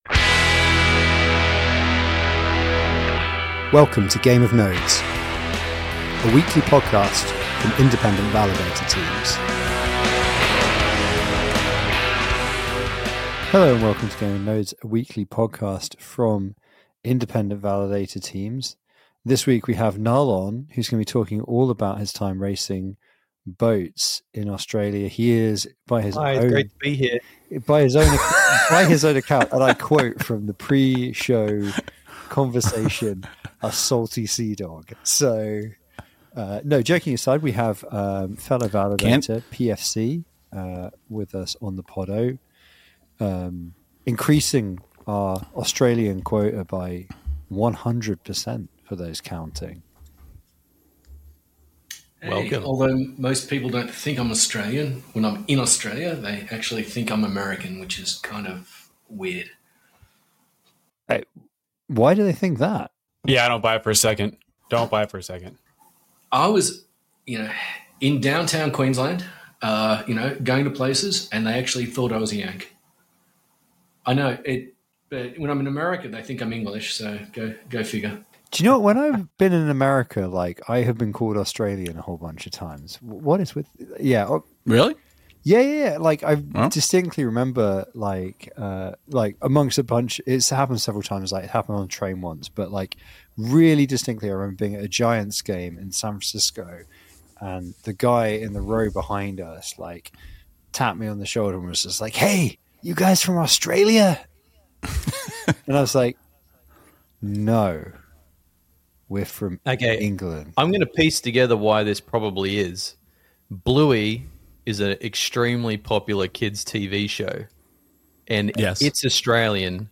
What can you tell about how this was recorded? We stream LIVE on every Wednesday @ 9:00PM UTC.